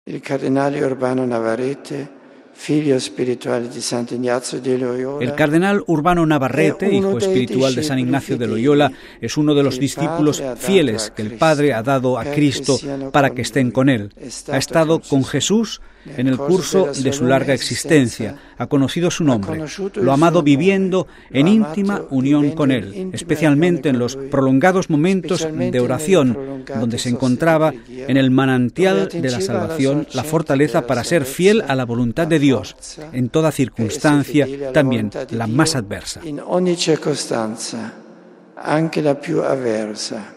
Después de la audiencia general, en la Basílica de San Pedro, en las exequias del «querido y venerado cardenal Urbano Navarrete, que el pasado lunes, a la edad de noventa años, concluyó su larga y fecunda peregrinación terrenal», Benedicto XVI ha hecho hincapié en estas palabras del profeta Daniel, enlazándolas con la vida de este purpurado español de la Compañía de Jesús - ferviente servidor de la Iglesia e insigne jurista - que el mismo Pontífice había creado cardenal en 2007.